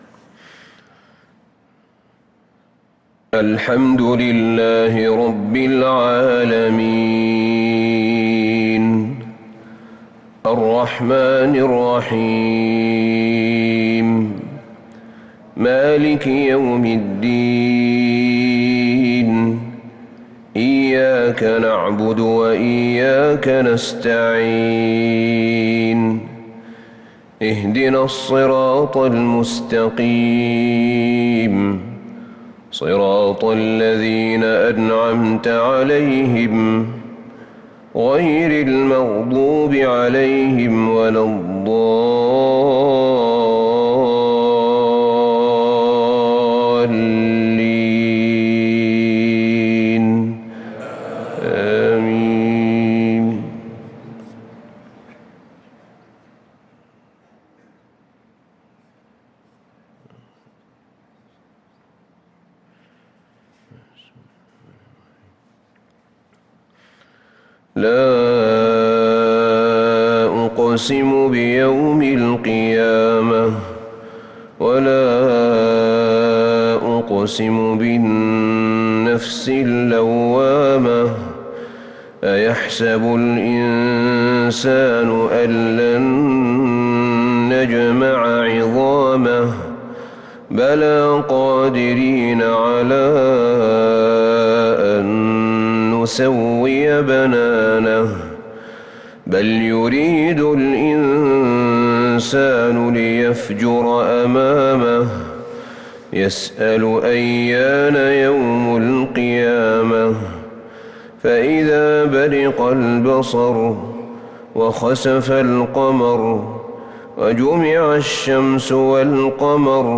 صلاة الفجر للقارئ أحمد بن طالب حميد 10 شوال 1443 هـ
تِلَاوَات الْحَرَمَيْن .